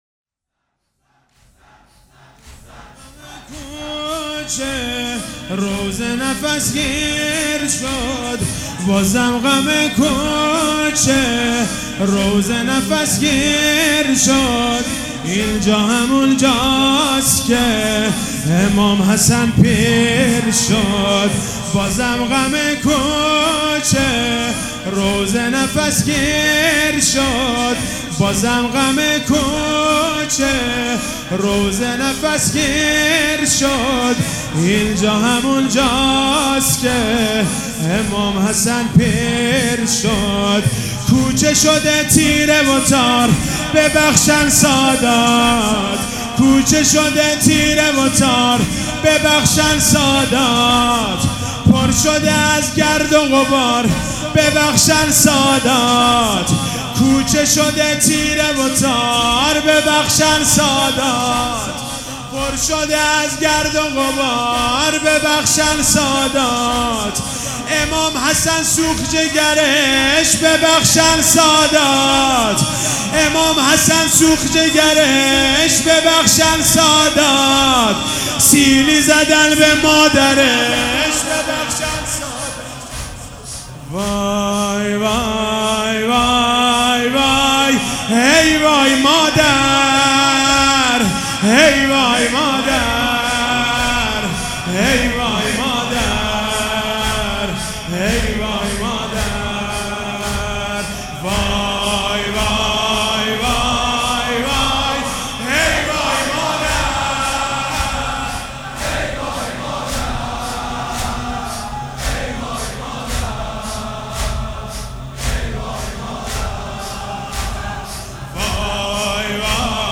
مداح
مراسم عزاداری شب پنجم